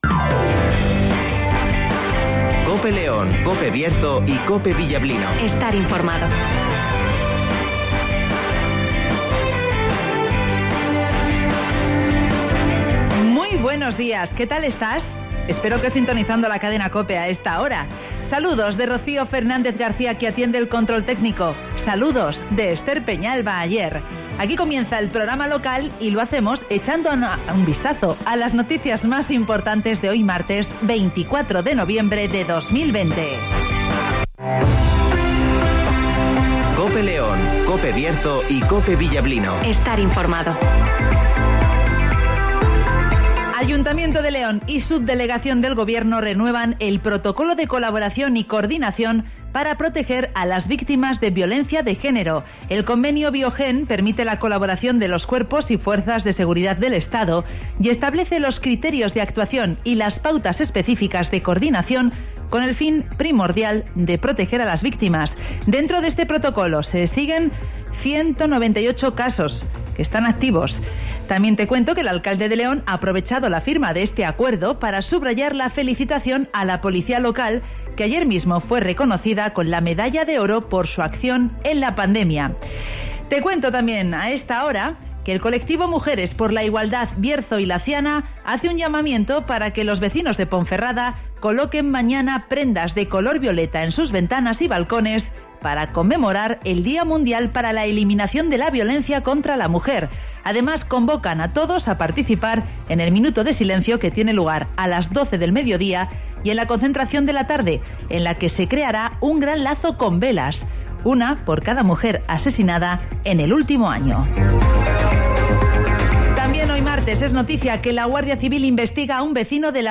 Avance informativo, El Tiempo (Neucasión) y Agenda (Carnicerias Lorpy)